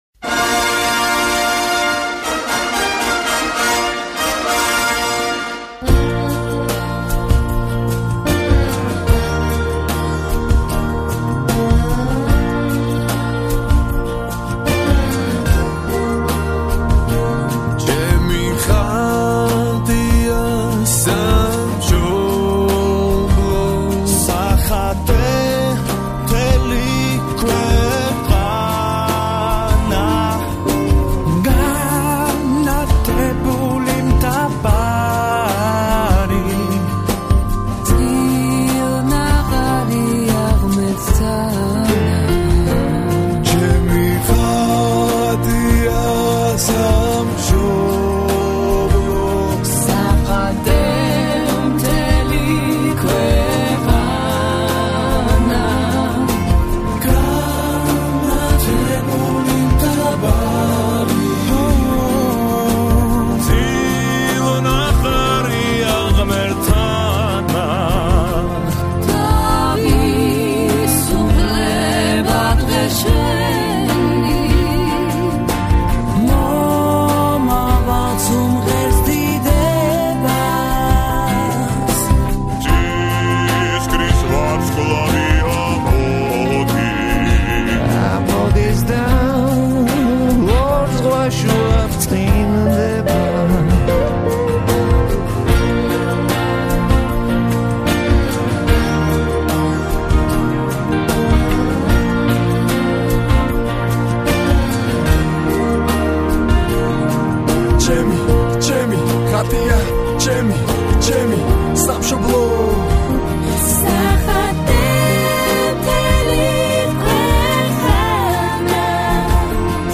Государственный гимн